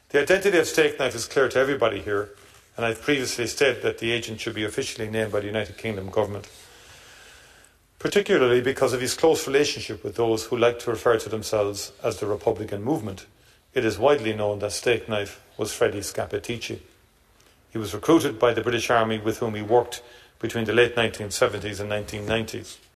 The Taoiseach has named the former British agent Stakeknife as Freddie Scappaticci in the Dáil.